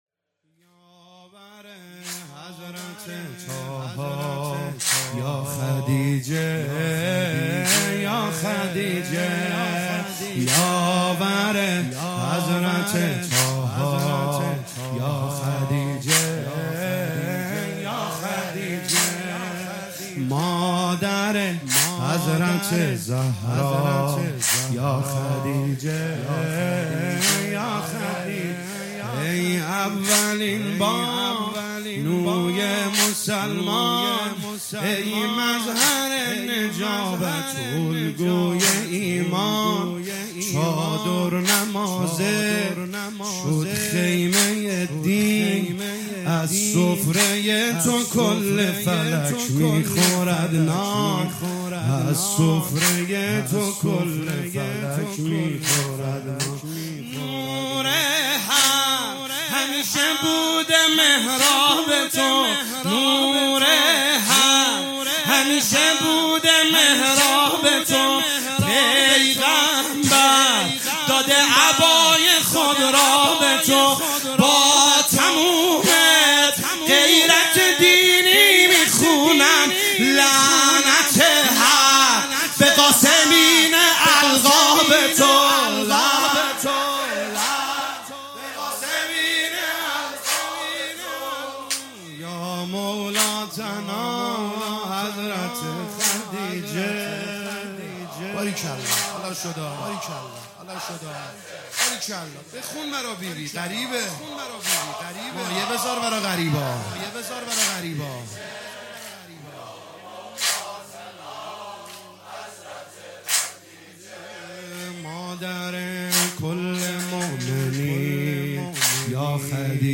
روضه هفتگی